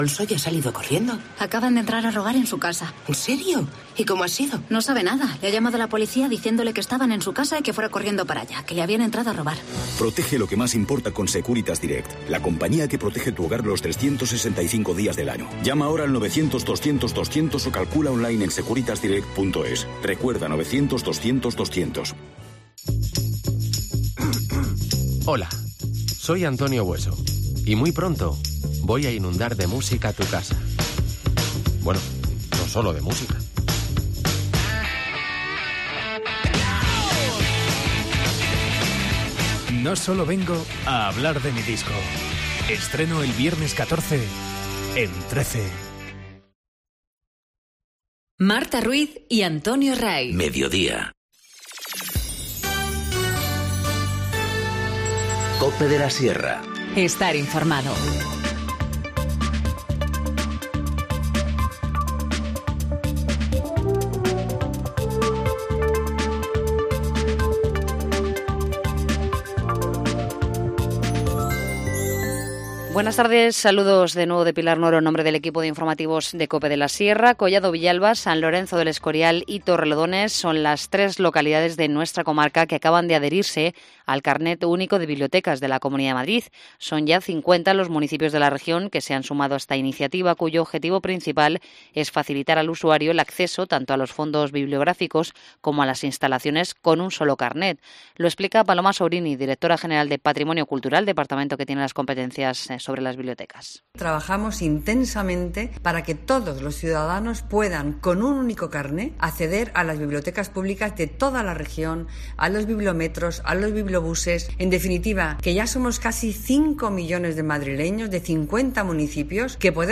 Informativo Mediodía 11 dic- 14:50h